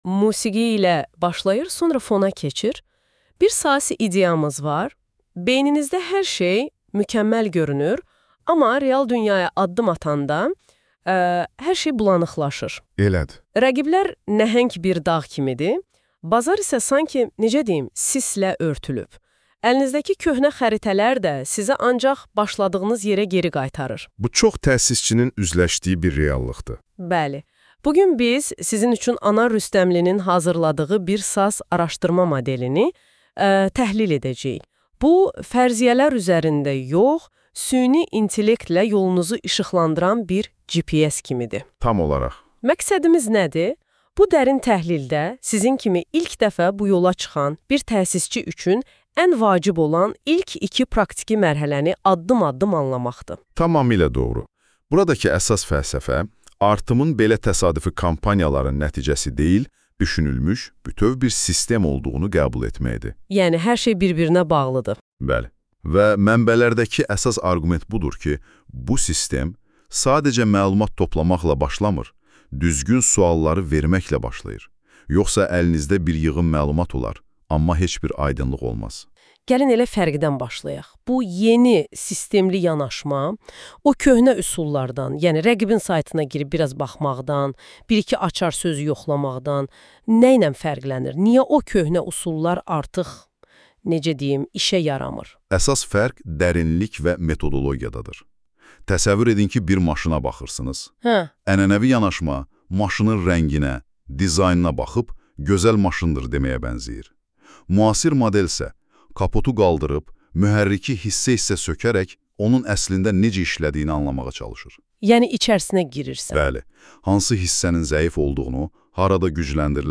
Araşdırmanı podcast kimi dinə! (NotebookLM ilə yaradılıb)